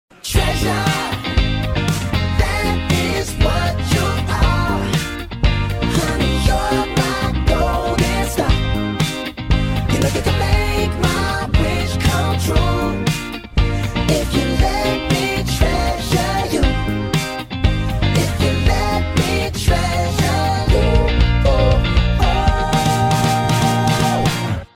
Playing The Funkiest Riff On Sound Effects Free Download